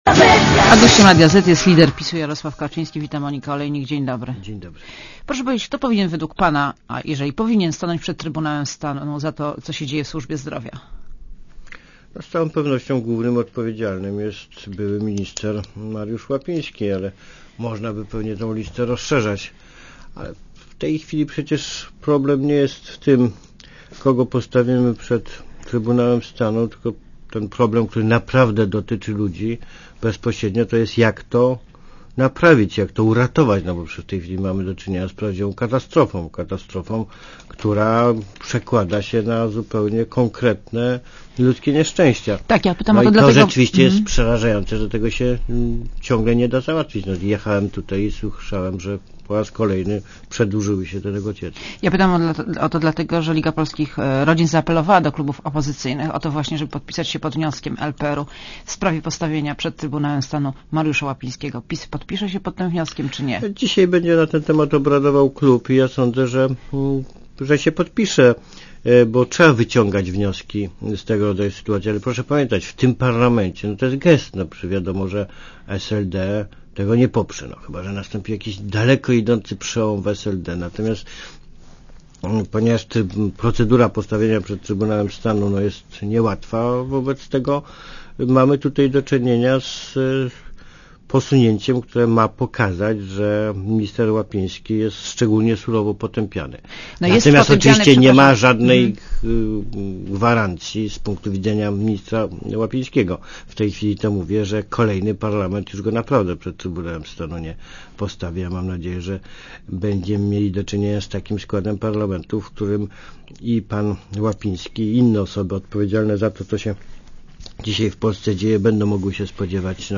Posłuchaj wywiadu A gościem Radia Zet jest lider PiSu Jarosław Kaczyński .